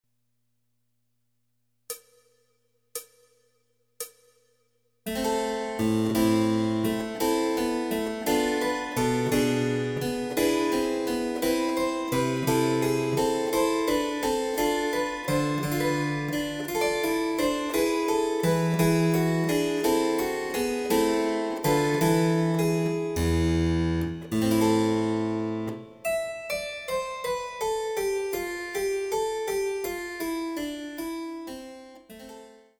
（ピッチはすべてモダンピッチ。ただし「正解」のほうには奏者が自由につけたトリルなどが入っているところがあります。）